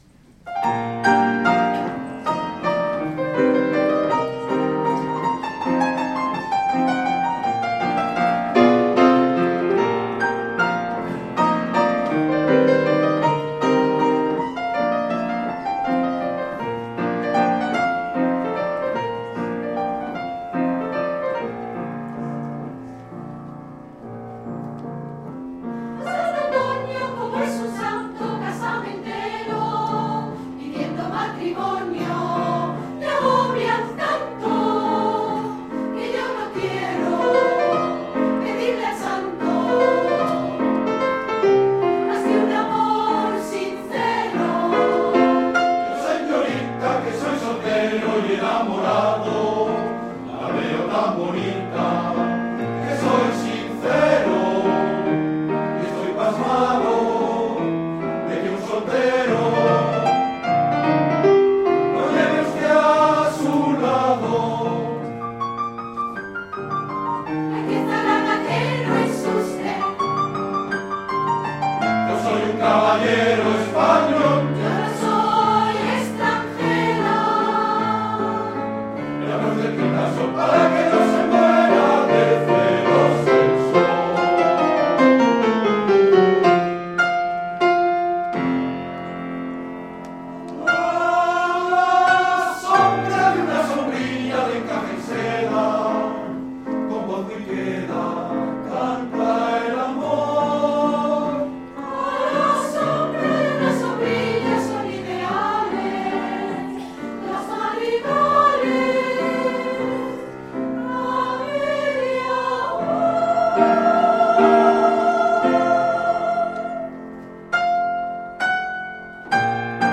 Es una  muestra de  canciones de diferentes estilos que hemos interpretado en los Conciertos (las grabaciones son en directo).